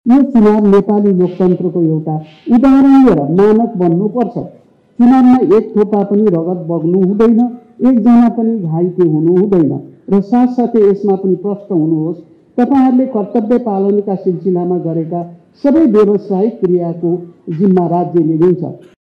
काठमाडौँ – जेनजी आन्दोलनको बलमा भदौ २७ गते प्रधानमन्त्रीमा नियुक्त पूर्वप्रधानन्यायाधीश सुशीला कार्कीले पहिलो पटक राष्ट्रिय सभाको बैठकमा सम्बोधन गरेकी छन्।
राष्ट्रिय सभाका सदस्यहरूले राखेको जिज्ञासाको जवाफ दिन उनी रोष्ट्रममा उभिइन्।